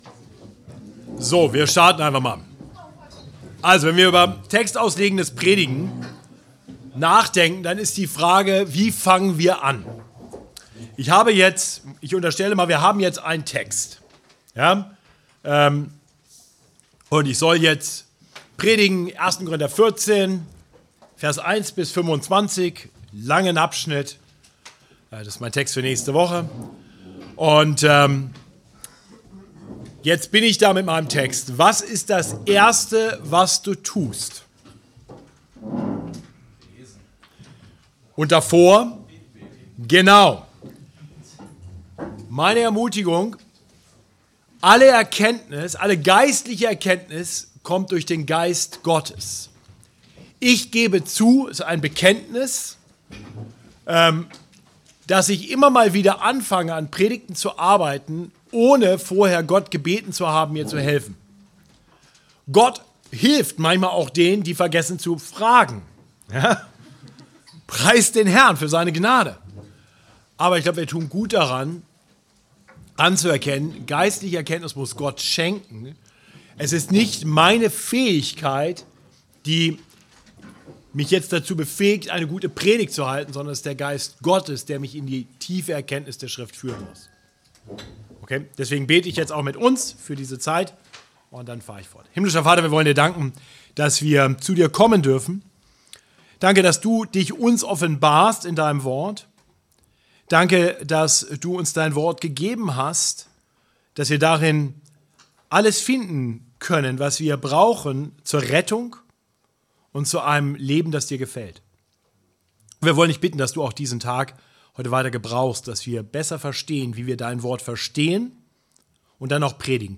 Seminar Auslegungspredigt Teil 2: Den Text in seinem ursprünglichen Kontext verstehen